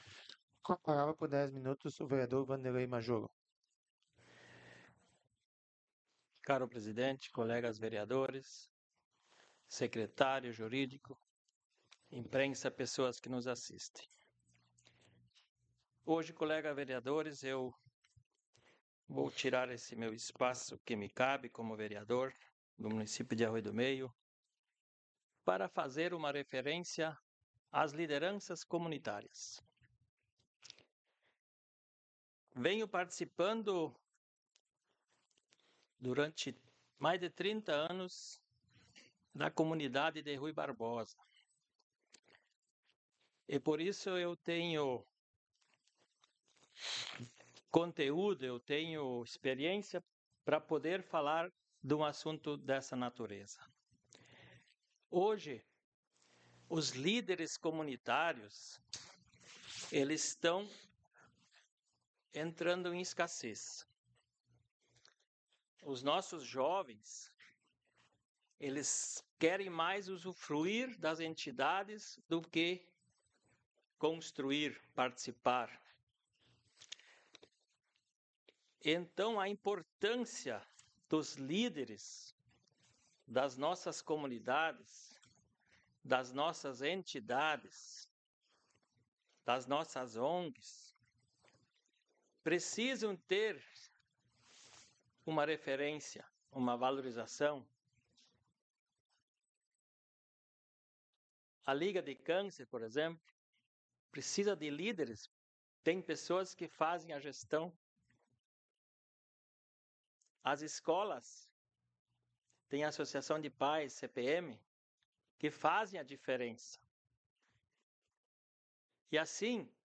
Áudio das Sessões Vereadores